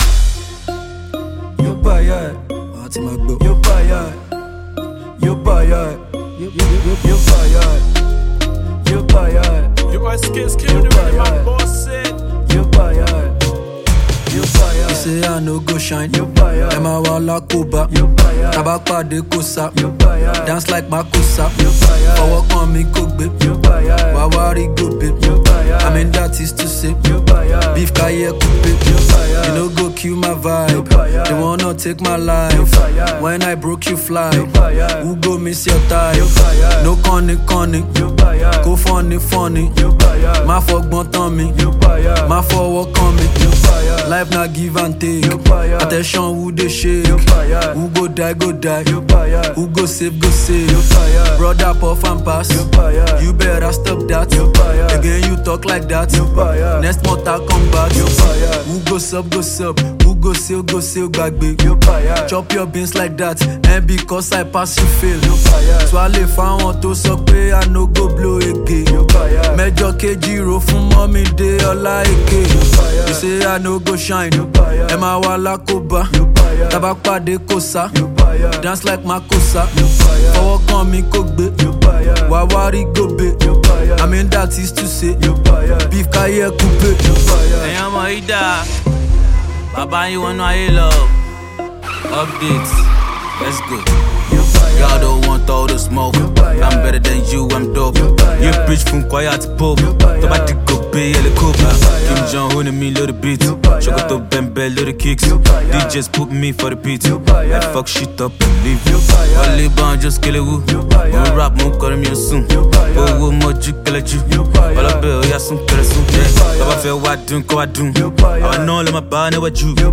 is an up tempo song to begin the year